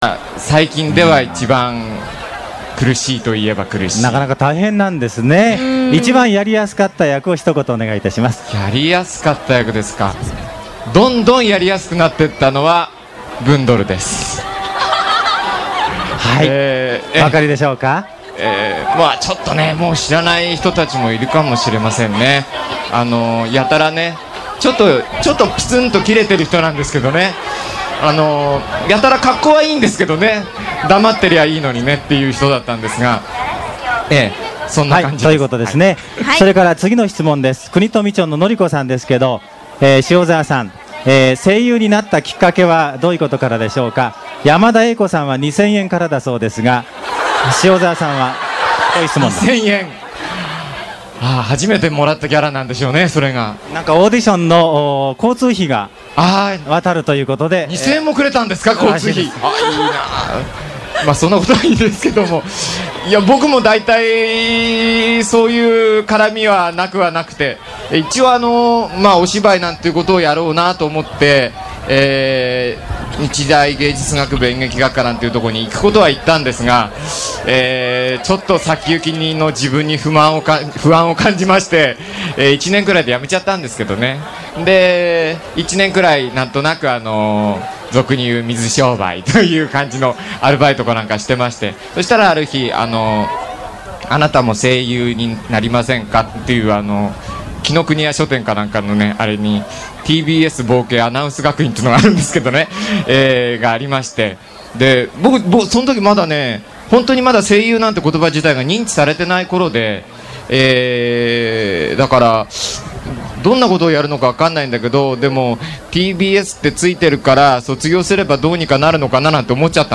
塩沢さんはゲップをこらえて宮崎弁に挑戦しました。